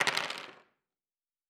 Dice Multiple 8.wav